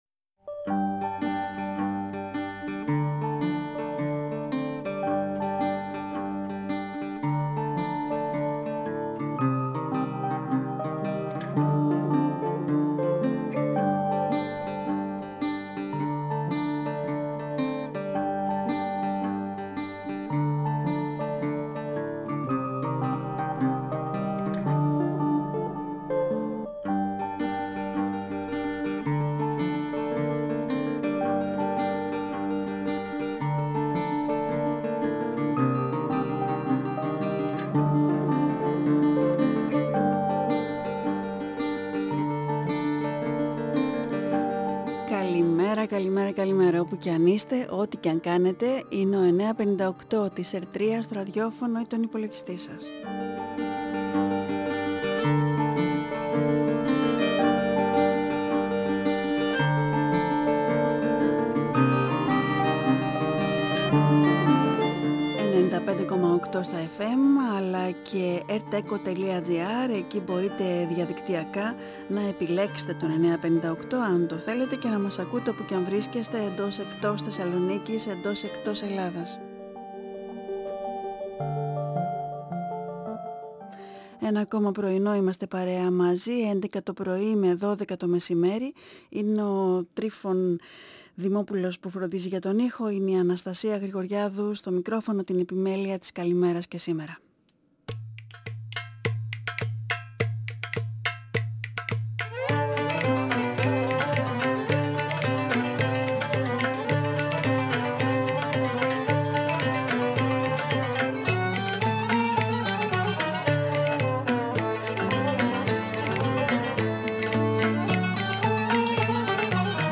Η συνέντευξη πραγματοποιήθηκε την Τετάρτη 5 Οκτωβρίου 2022 στην εκπομπή “Καλημέρα” στον 9,58fm της ΕΡΤ3